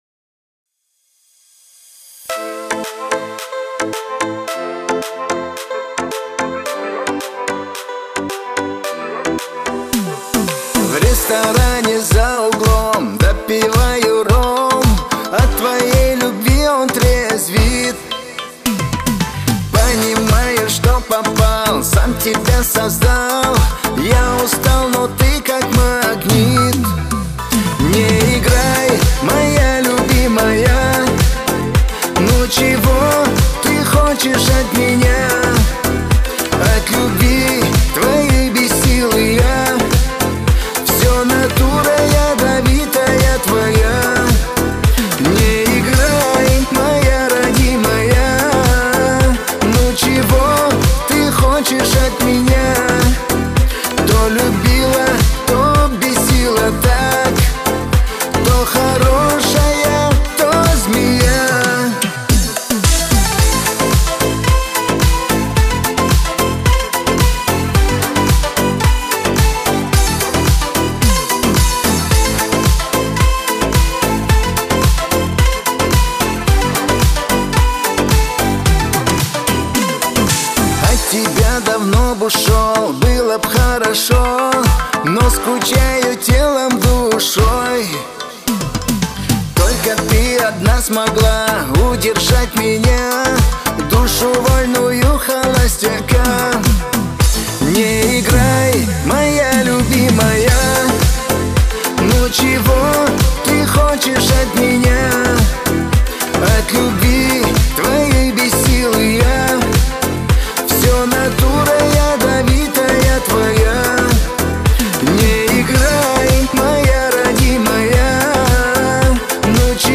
Скачать музыку / Музон / Музыка Шансон